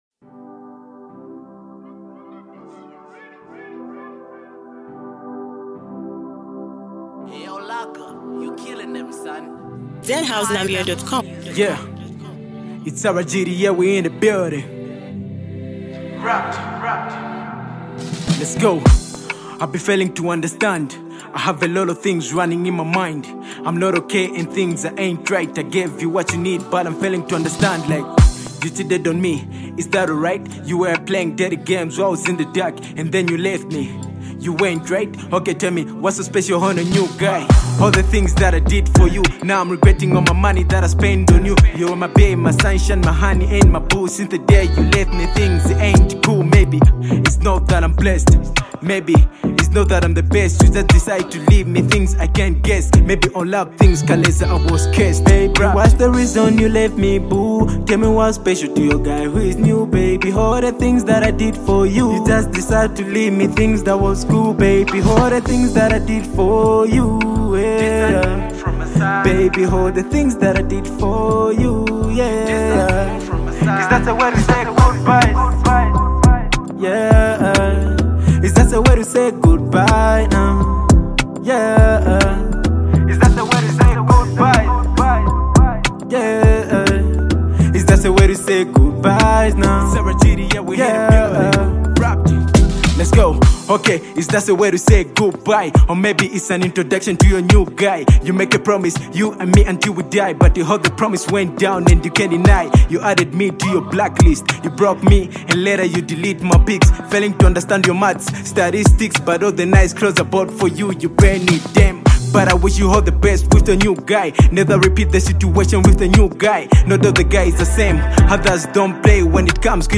Rising rap artist
With raw lyrics and a heartfelt delivery
A must-listen for fans of emotional rap!